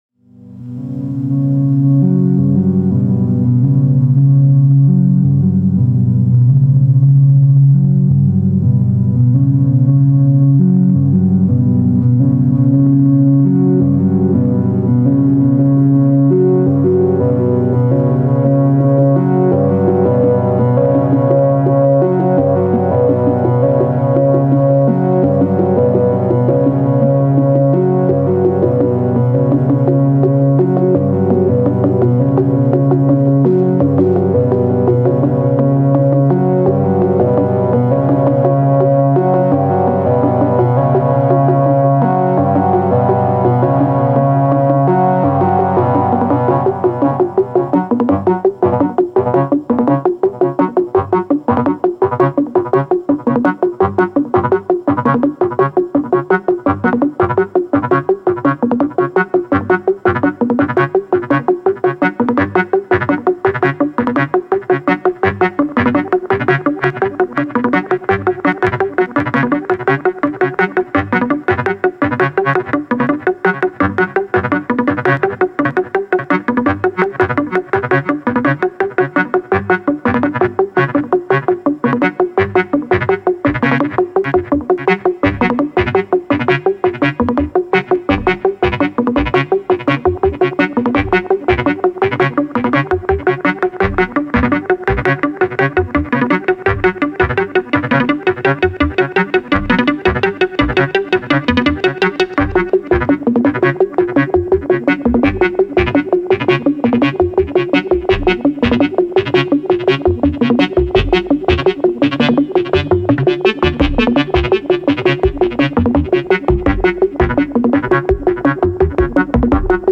Music only 😉